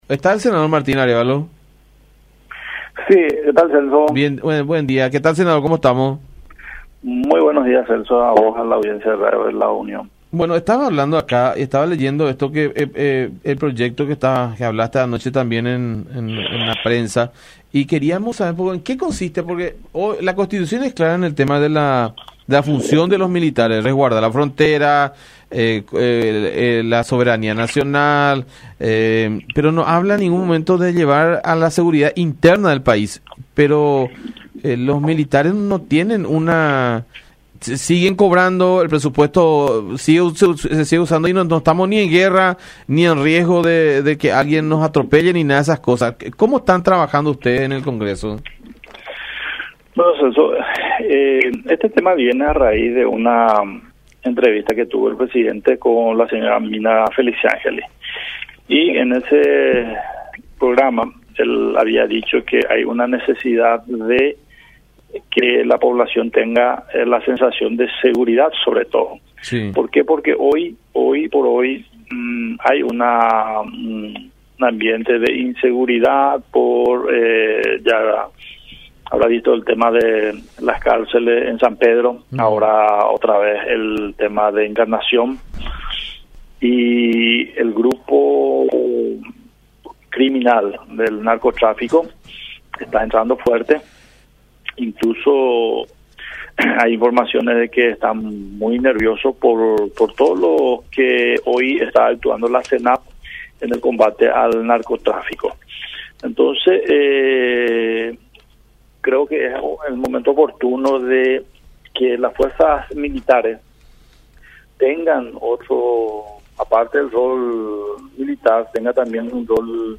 “Los militares son una fuerza ociosa que deben tener funciones acordes a la necesidad actual. Es oportuno darle otras actividades en cuanto a la seguridad interna”, consideró Arévalo en contacto con La Unión, haciendo énfasis en el combate al crimen organizado.
20-Mario-Martín-Arévalo-Senador-Nacional.mp3